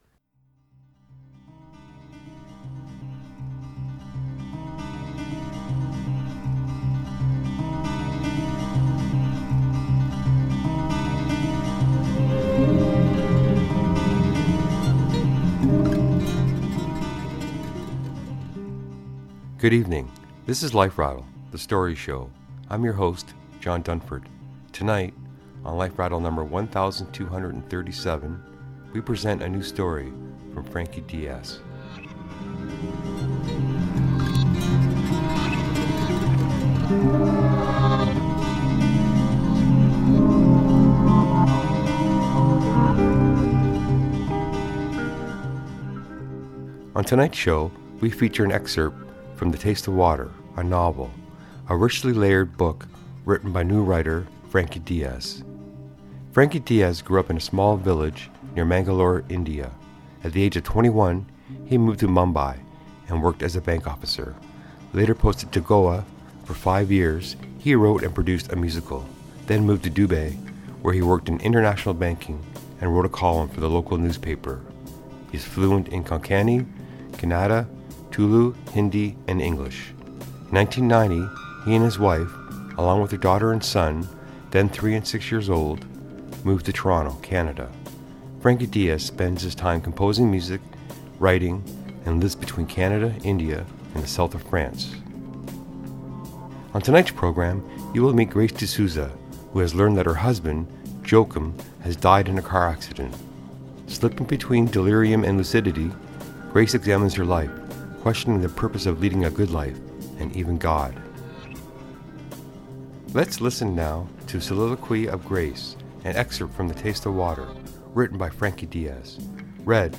In tonight's reading, you will meet Grace De Souza, who has learned that her husband, Joachim, has died in a car accident. Slipping between delirium and lucidity, Grace examines her life, questioning the purpose of leading a good life—and even God.